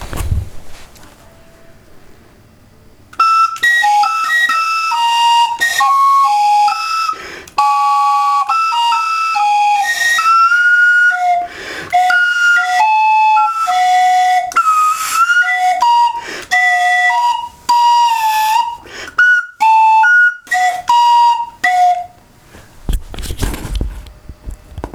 TOY WATER TRUMPET demo.WAV